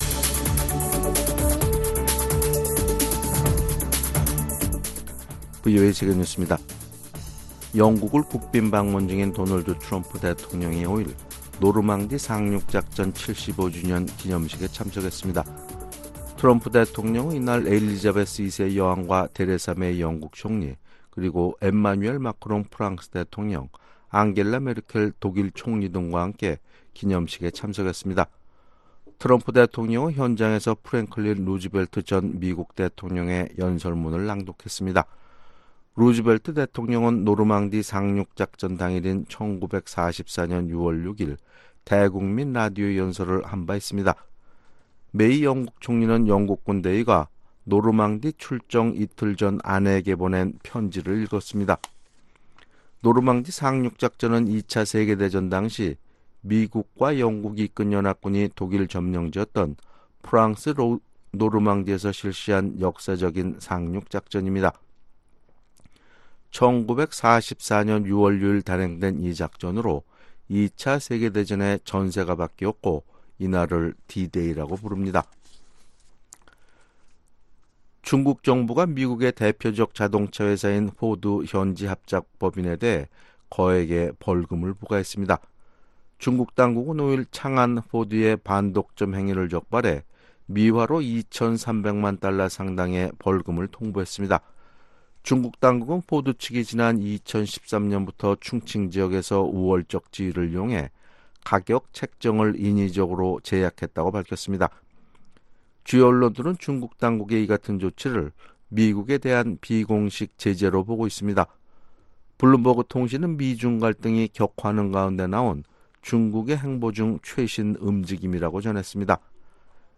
VOA 한국어 아침 뉴스 프로그램 '워싱턴 뉴스 광장' 2019년 6월 6일 방송입니다. 스웨덴 정부는 대북 인도적 지원이 도움을 가장 필요로 하는 계층에 전달되도록 하기 위해 자체적인 ‘감시 프로젝트’를 이행하고 있다고 밝혔습니다. 미국이 북한산 석탄 수출에 이용된 것으로 의심하는 북한 선박 일부가 여전히 운행을 계속하고 있는 것으로 나타났습니다.